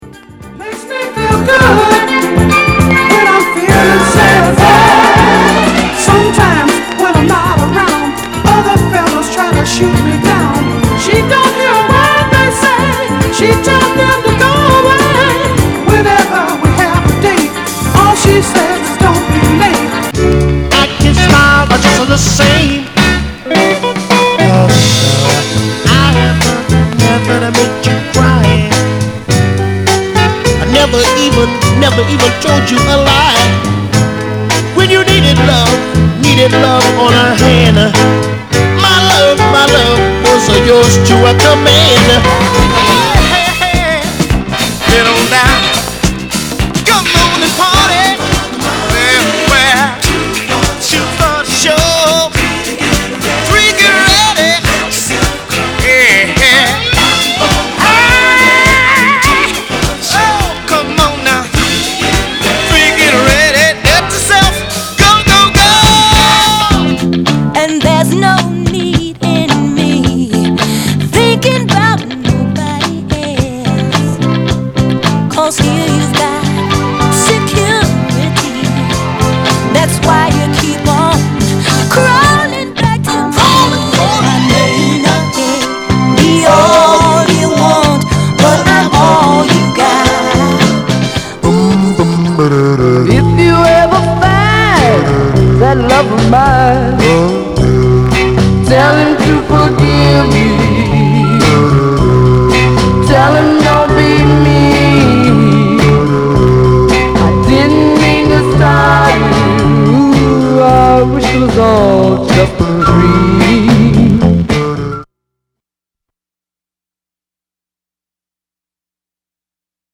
JDirectItems Auction 音樂 黑膠唱片 R&B、靈魂樂
類別 R&B、靈魂樂